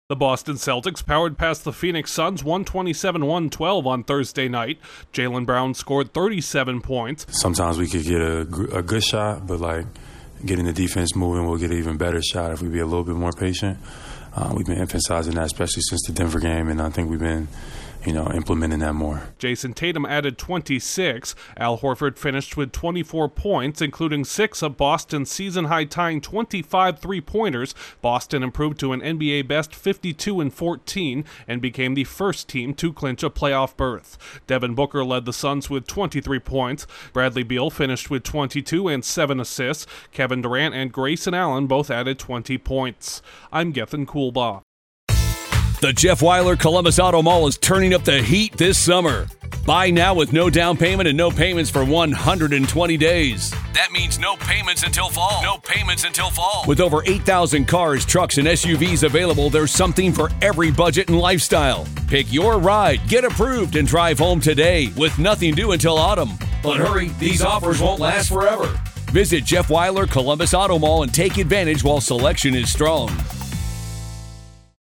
The Celtics earn a comvincing win over the Suns. Correspondent